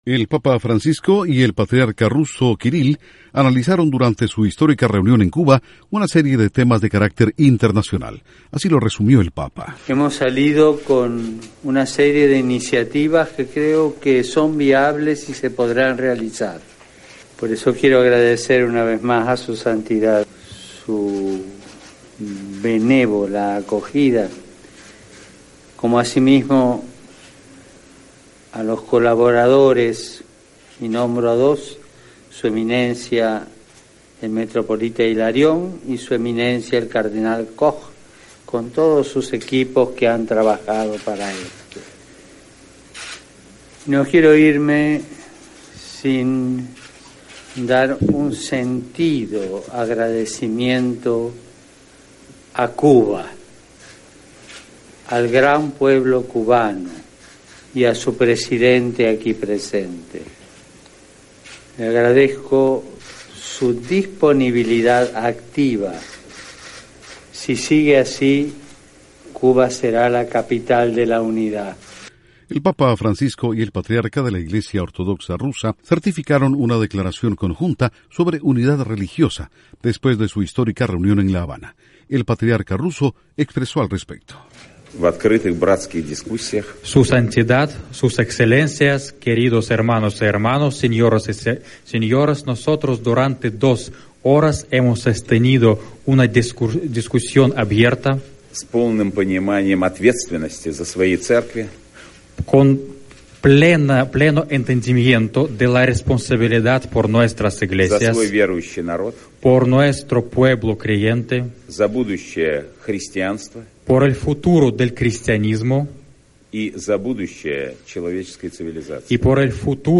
El papa Francisco y el Patriarca ruso Kirill abogan en Cuba por el restablecimiento de la unidad del cristianismo, fracturado por un cisma milenario. Informa